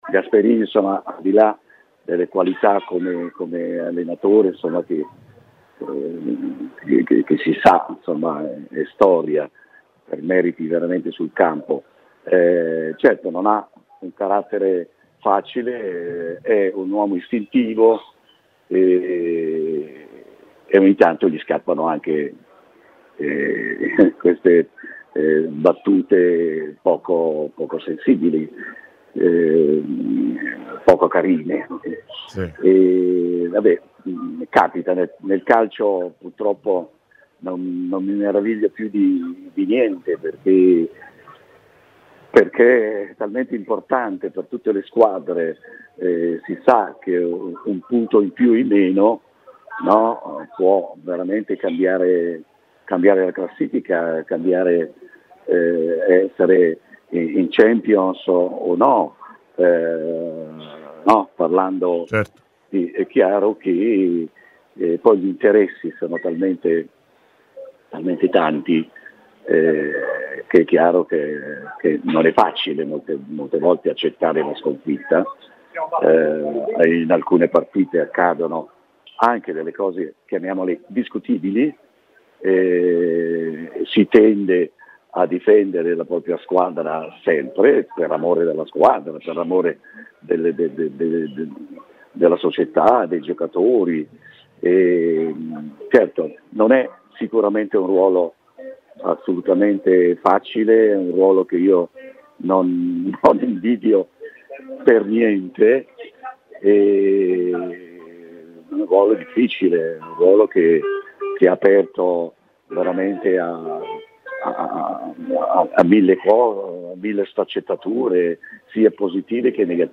Intervenuto a Radio FirenzeViola per dire la sua sulla querelle Gasperini-Fiorentina, Roby Facchinetti, cantante dei Pooh e tifoso dell'Atalanta, ha parlato così della tensione che si è creata negli ultimi giorni: "Gasperini non ha un carattere facile, è un uomo istintivo ed ogni tanto fa anche queste battute poco sensibili.